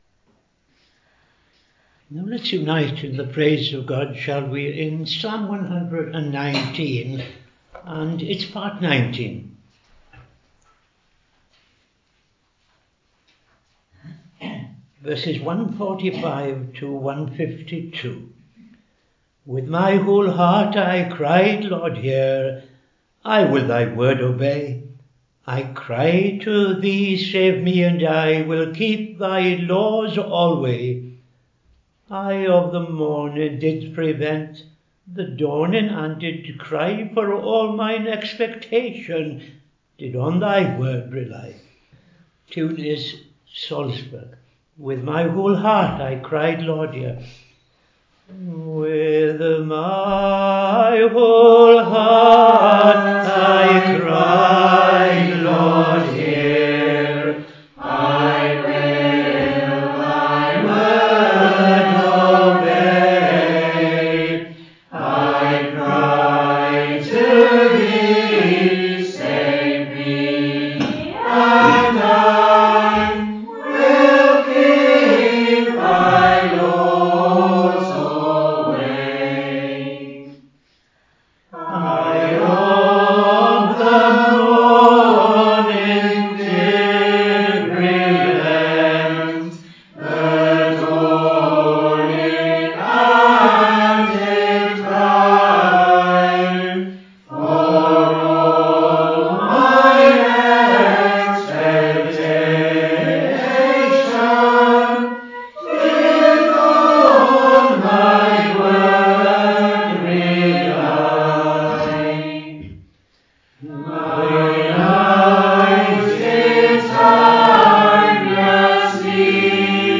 Morning Service
Tune Dunfermline
Public Prayer followed by N.T. Reading Luke 6:1-26